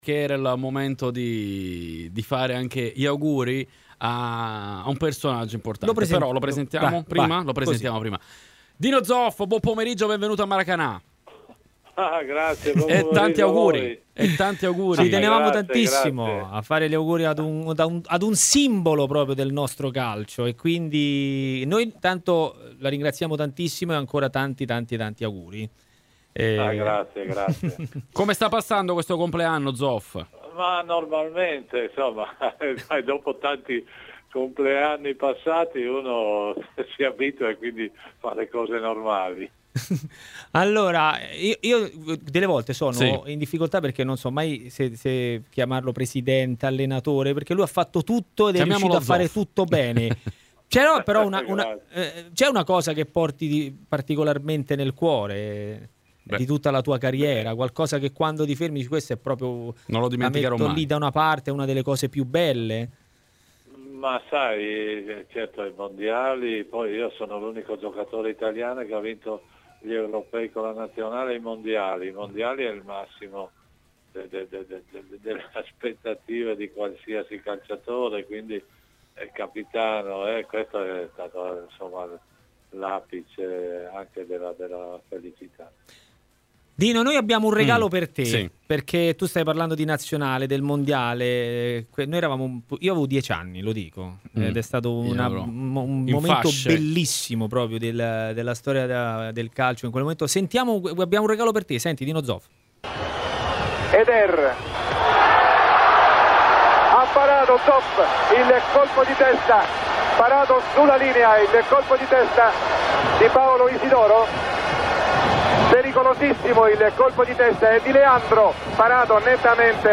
Un compleanno che anche RMC Sport ha voluto festeggiare in diretta con il portiere simbolo della Juventus e della Nazionale, con la quale nel 1982 ha trionfato nel Mondiale spagnolo: "Sicuramente quello è stato un momento speciale, il momento più bello".
Dino Zoff ,intervistato